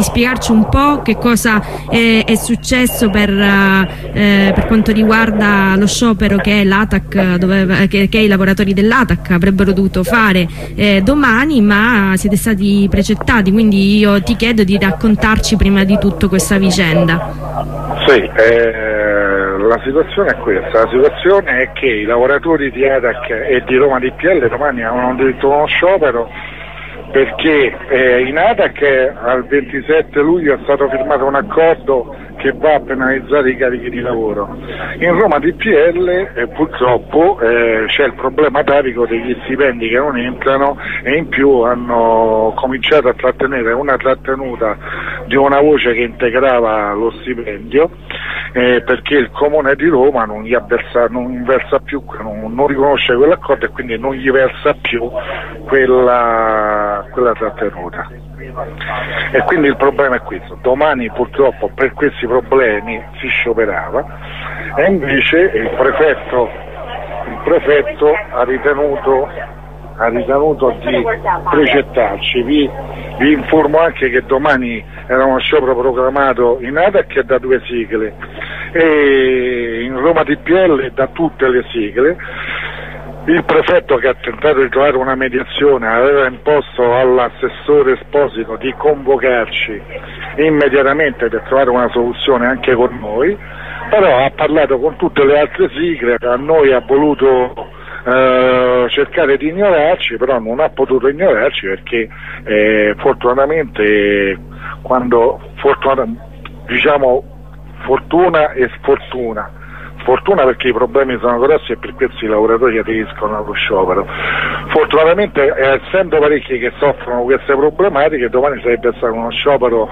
Ai microfoni di radio ondarossa un delegato usb dell'atac di Roma.